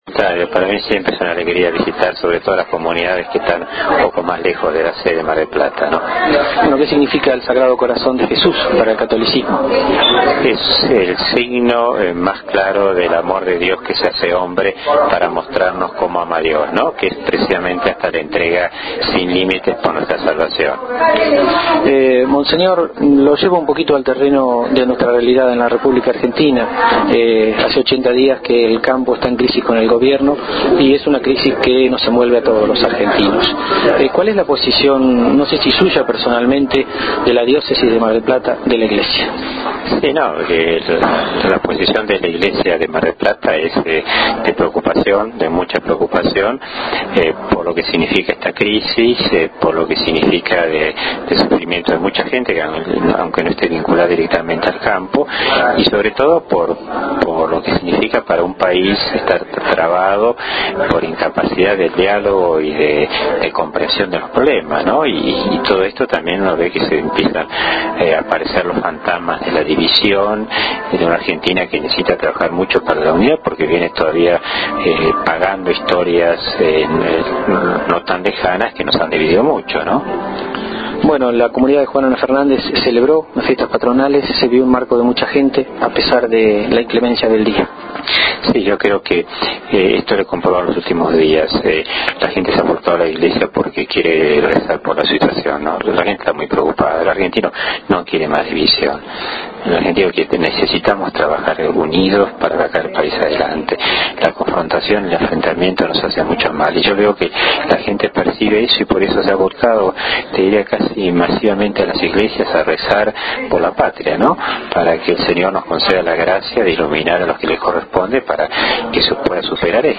Luego de la celebración hablamos con el Obispo, quien, entre otras cosas, nos dijo que se sentía muy contento de venir a Juan N. Fernández a compartir esta fiesta. También expresó su preocupación por la actual crisis entre el gobierno y el campo.